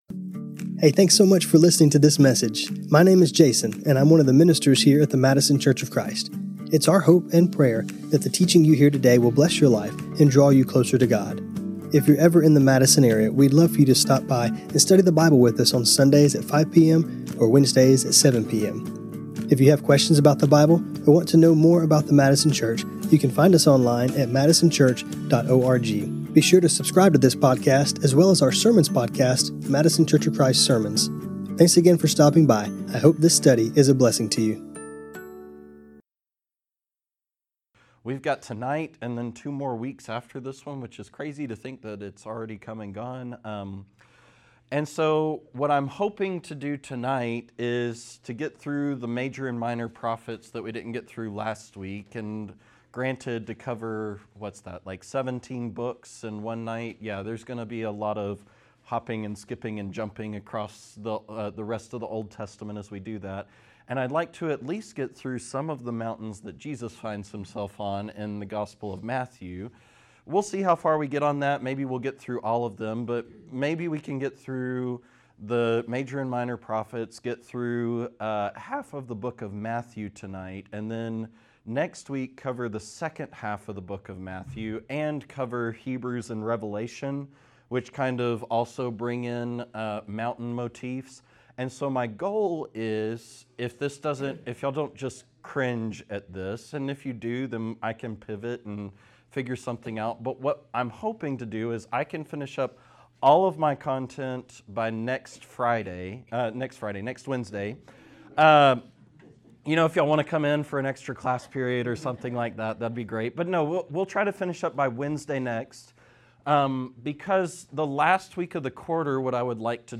This class was recorded on Jan 14, 2026 Check out the church archives including notes for this class Find us on Facebook.